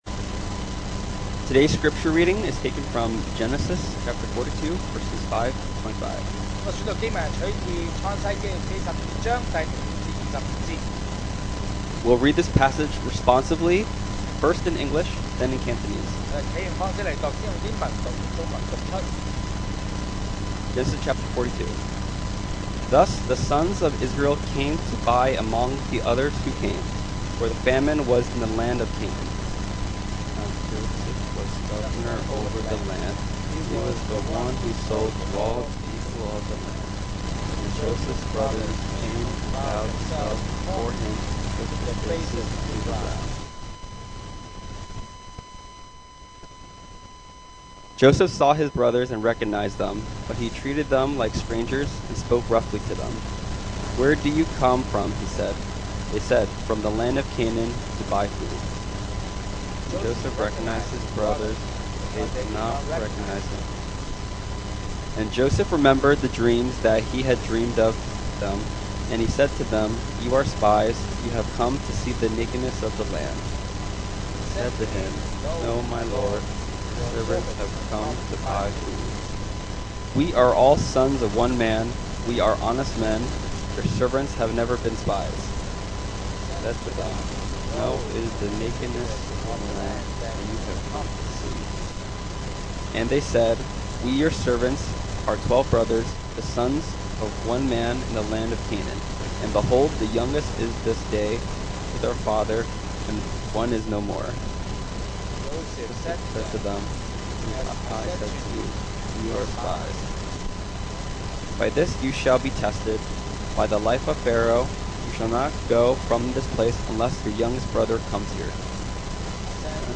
Series: 2025 sermon audios 2025年講道重溫 Passage: Genesis 42: 5-25 Service Type: Sunday Morning